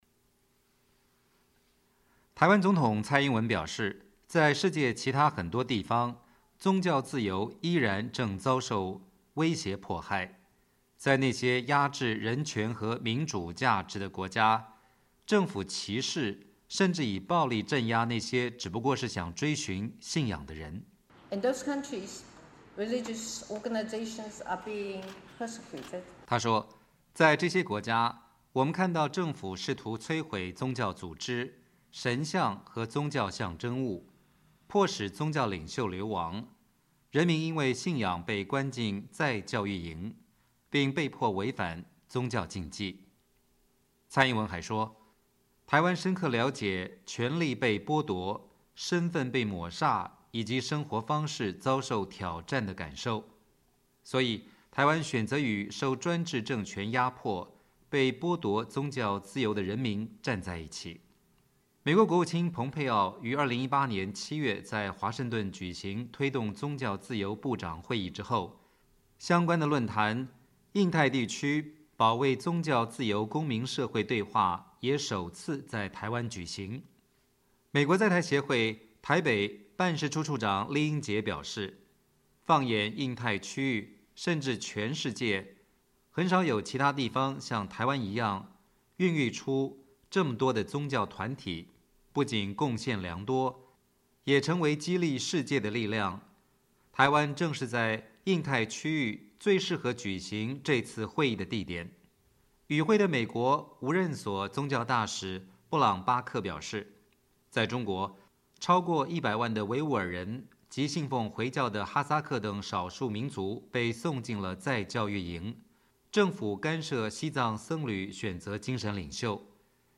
2019区域宗教自由论坛在台湾举行 蔡英文总统与美国宗教大使布朗巴克同台致词